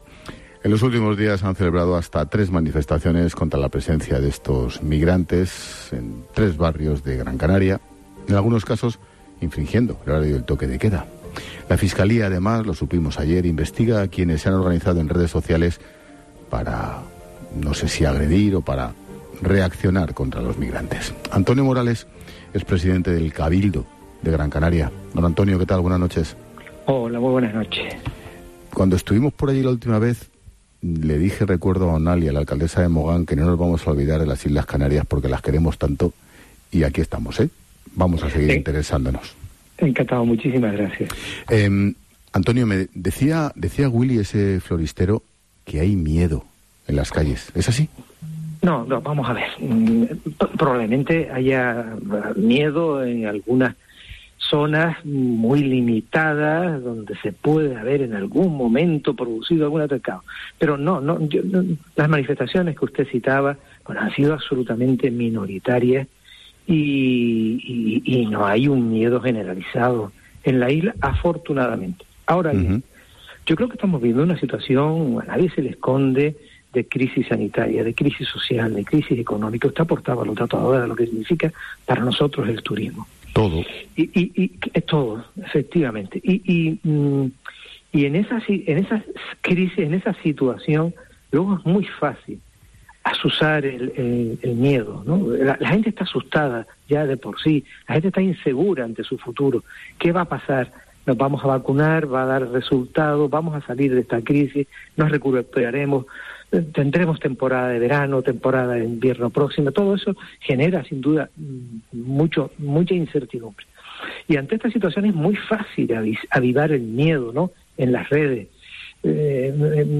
Antonio Morales, presidente del Cabildo de Gran Canaria, habla con Ángel Expósito en La Linterna